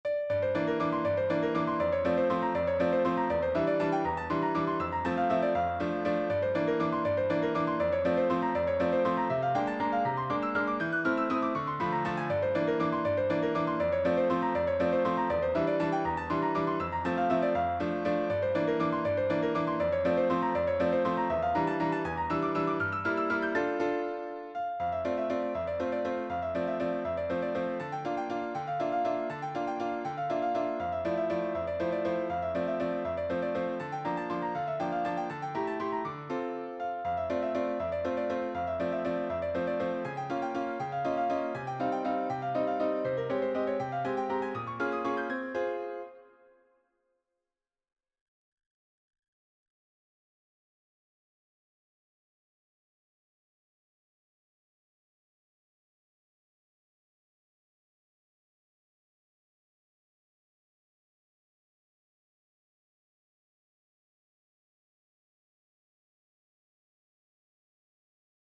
a new piece I'm working on - Piano Music, Solo Keyboard - Young Composers Music Forum